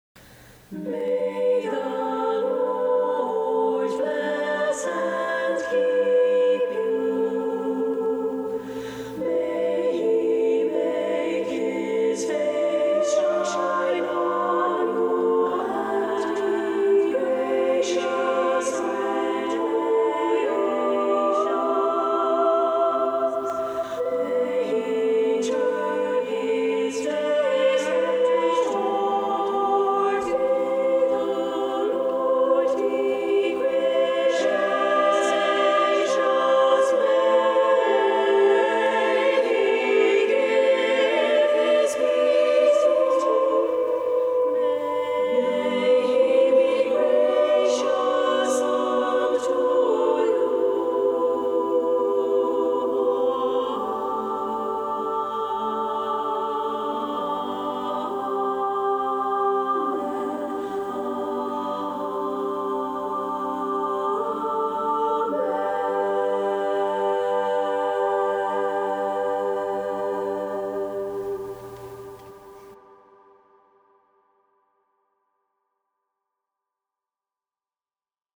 SSA with divisi